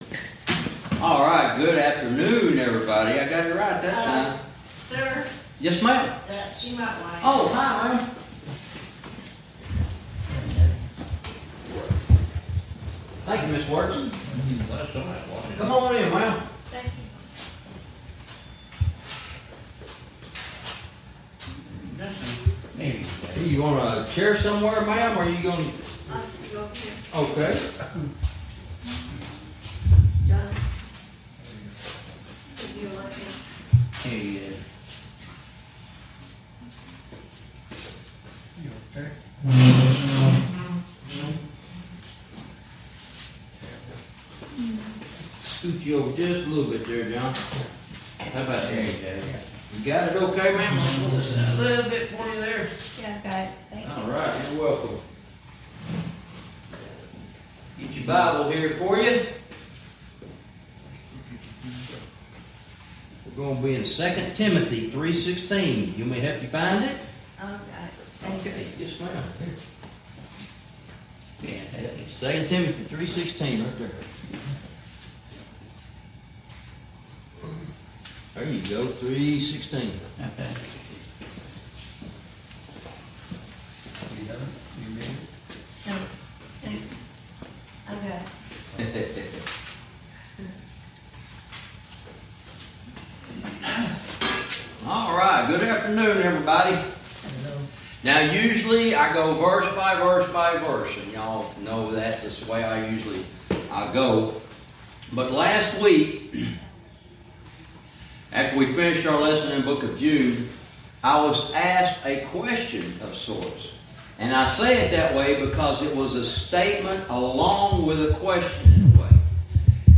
Verse by verse teaching - Lesson 55 - God's Word